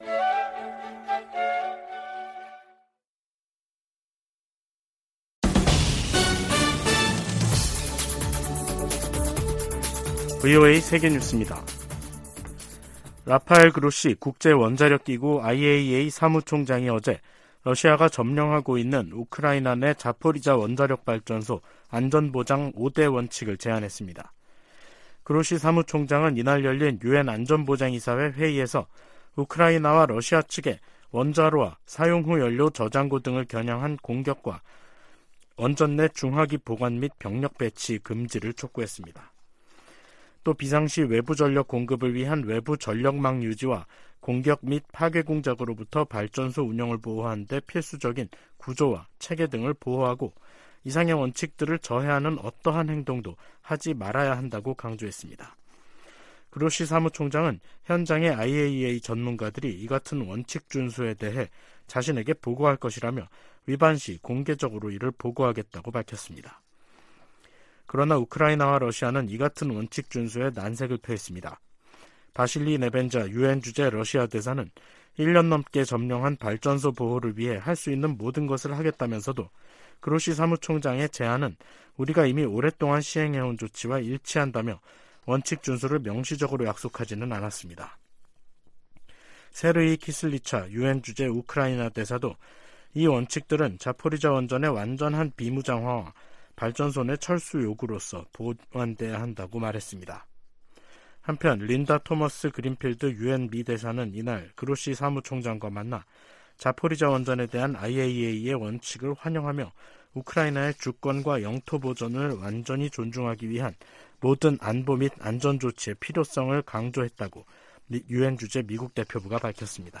VOA 한국어 간판 뉴스 프로그램 '뉴스 투데이', 2023년 5월 31일 2부 방송입니다. 북한은 31일 정찰위성 발사가 엔진고장으로 실패했다고 발표했습니다.